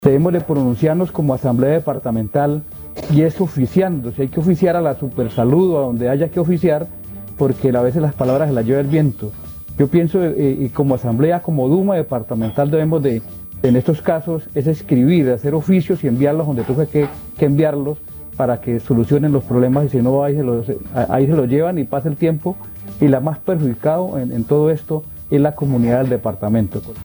La salud, debate en la Asamblea del Guaviare.